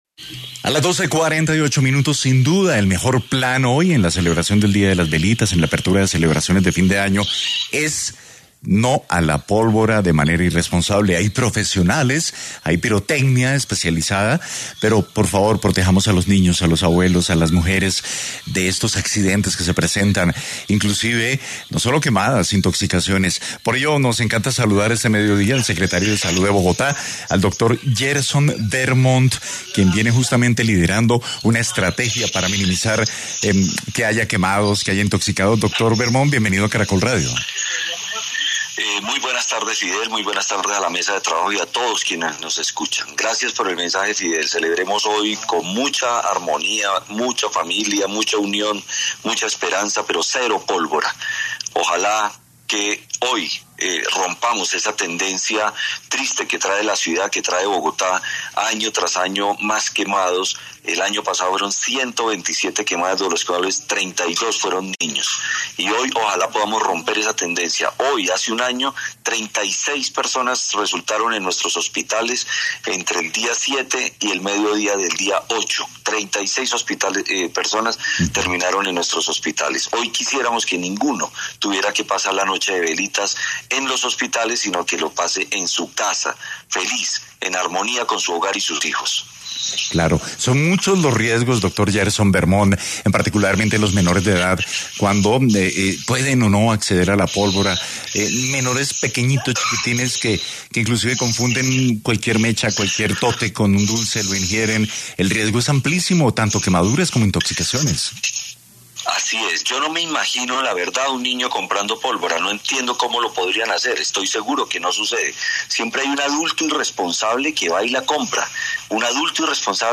El secretario de salud del distrito Gerson Orlando Bermont en dialogo con Caracol Radio, reiteró el llamado, para que el uso de pólvora por personas no expertas está prohibido en la capital debido a su alta peligrosidad.